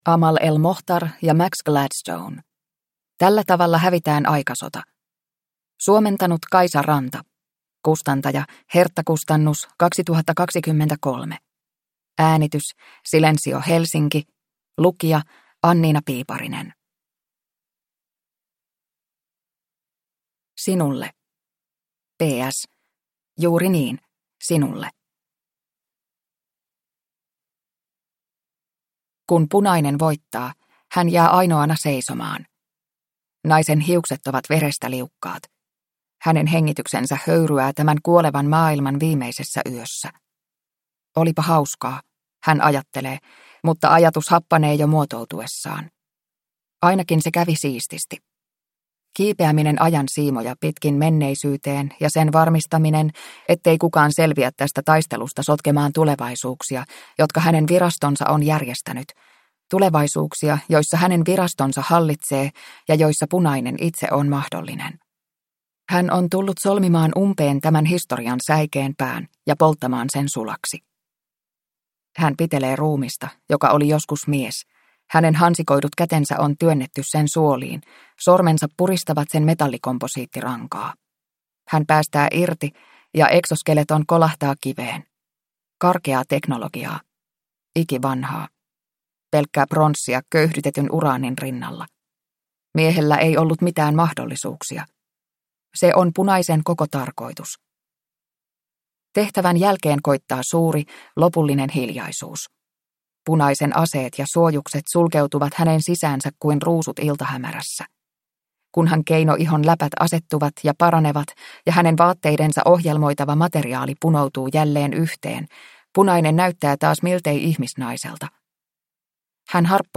Tällä tavalla hävitään aikasota – Ljudbok